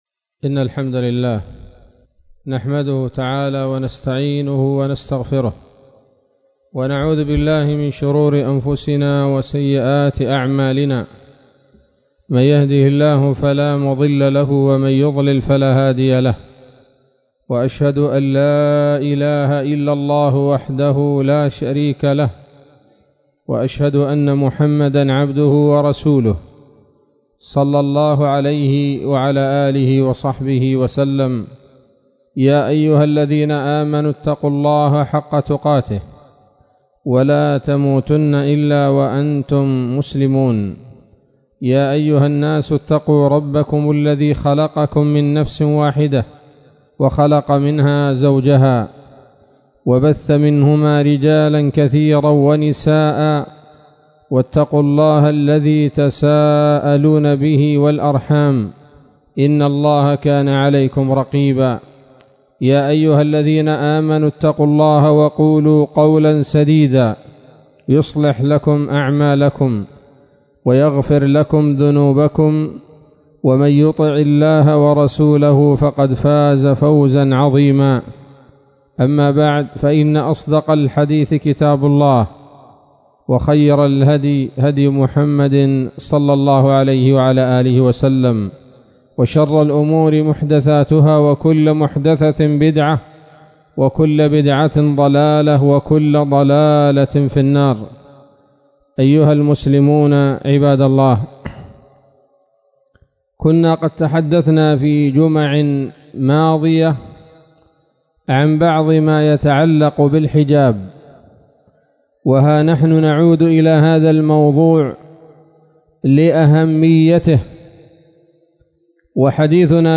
خطبة جمعة بعنوان: (( مثالب التبرج والسفور )) 22 من ذي الحجة 1440 هـ